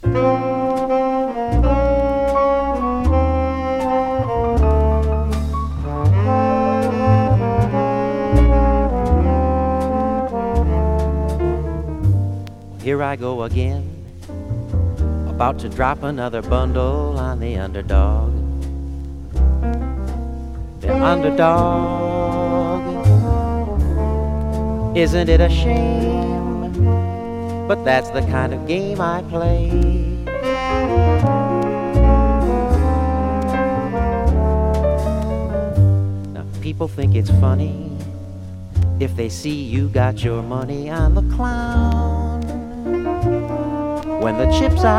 Jazz, Swing, Vocal　USA　12inchレコード　33rpm　Stereo